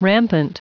Prononciation du mot rampant en anglais (fichier audio)
Prononciation du mot : rampant